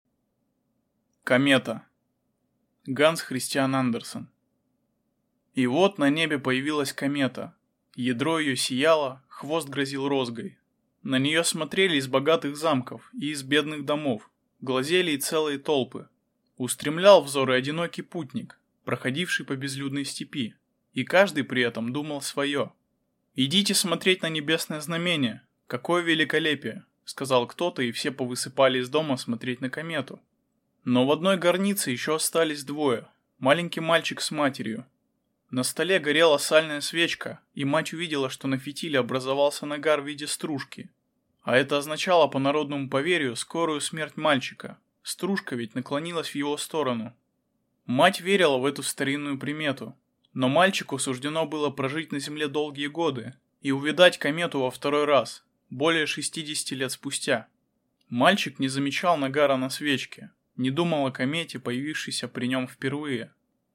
Aудиокнига Комета